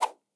quick transitions (1).wav